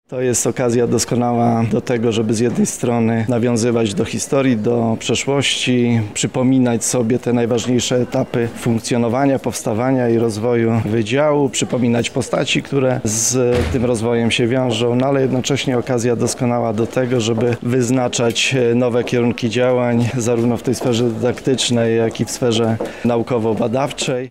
Dzisiaj odbyła się natomiast oficjalna część, w której udział wzięli przedstawiciele władz rektorskich, dziekańskich oraz samorządowych.
50 lecie, Złote Gody, wyjątkowy jubileusz dla wydziału, ale i dla uniwersytetu– mówi profesor Radosław Dobrowolski, rektor UMCS